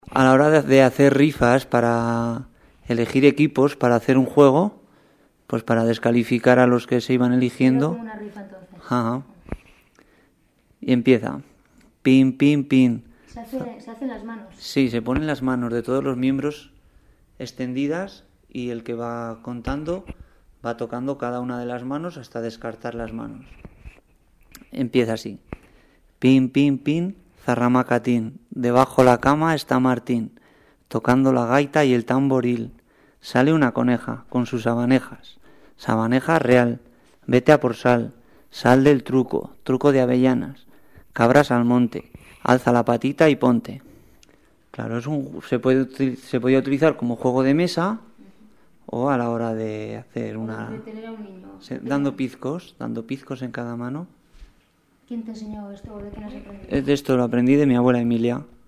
Clasificación: Cancionero
Lugar y fecha de grabación: Alberite, 14 de febrero de 2006